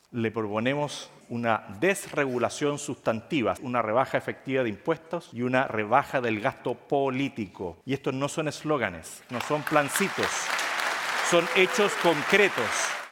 De esta manera, los aspirantes a La Moneda se reunieron en la región de Los Lagos, específicamente en el Teatro del Lago, en Frutillar, donde se está desarrollando el Salmón summit 2025.